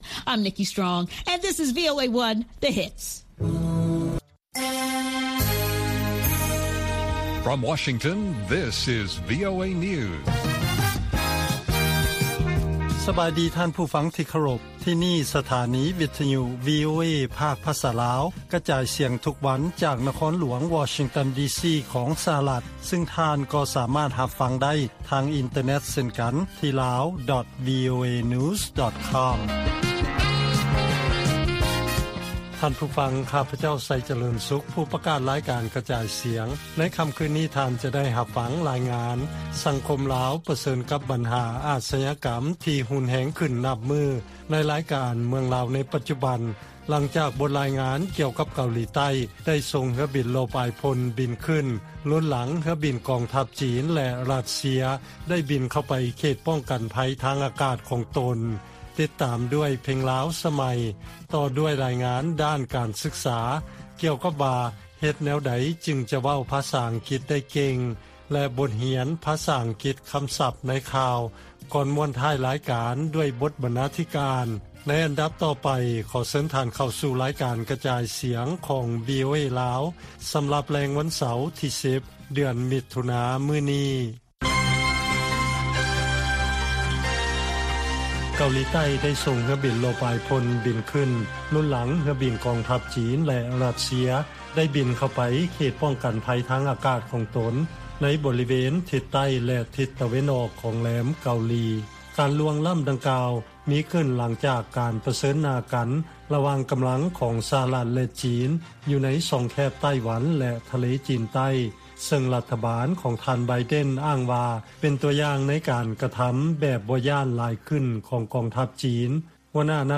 ລາຍການກະຈາຍສຽງຂອງວີໂອເອລາວ ວັນທີ 11 ມິຖຸນາ 2023